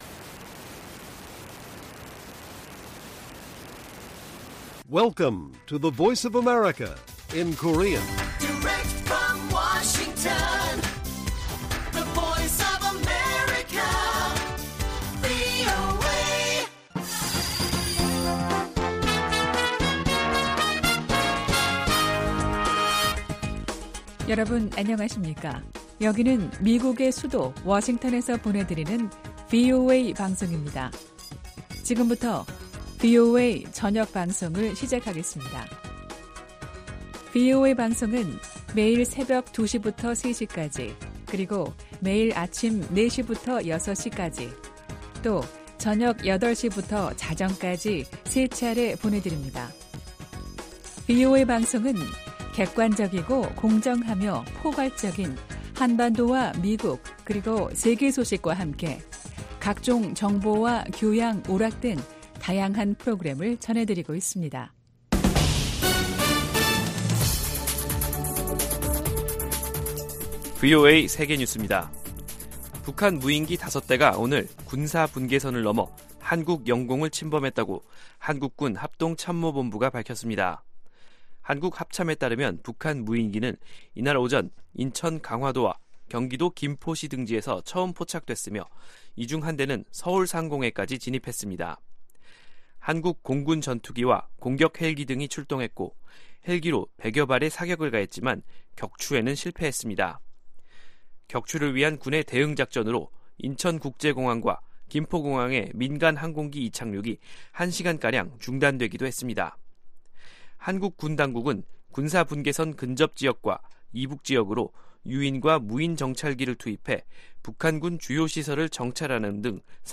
VOA 한국어 간판 뉴스 프로그램 '뉴스 투데이', 2022년 12월 26일 1부 방송입니다. 북한 무인기가 오늘 5년 만에 남측 영공을 침범해 한국군이 격추 등 대응작전을 벌였지만 격추에는 실패했습니다. 미국 국무부가 북한 정권의 단거리탄도미사일 발사를 규탄하면서 이번 발사가 유엔 안보리 결의에 위배된다고 지적했습니다.